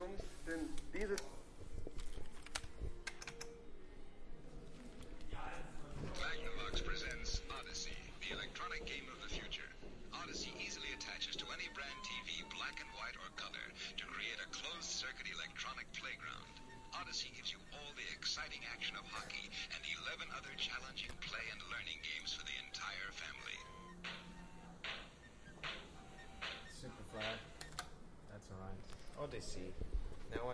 古董留声机播放音乐的样本
描述：古董留声机（COLUMBIA Grafonola 202）播放音乐的样本。
标签： 博物馆 78转唱片 匈牙利舞 留声机 怀旧的 老旧 78 小提琴 古董 勃拉姆斯 稀有 钢琴 低保真度 机械 噪声 黑胶唱片
声道立体声